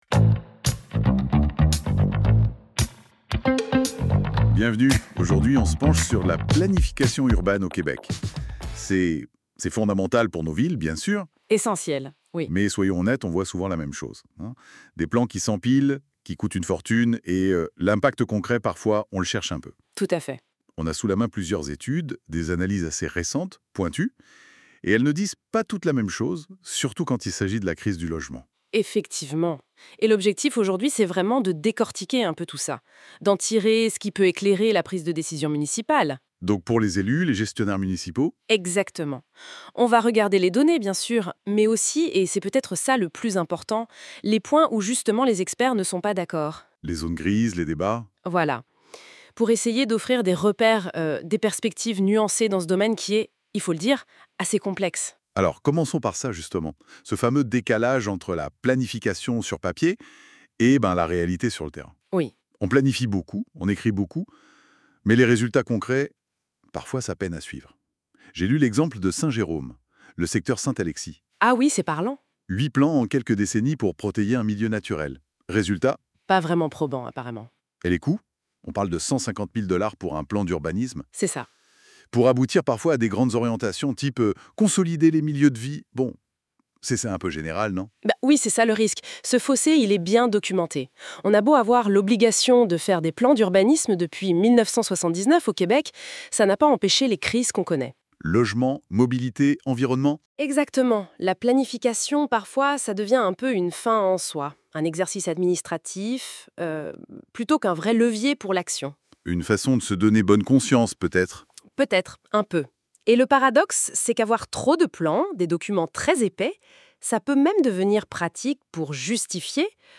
Ce podcast est généré par intelligence articifielle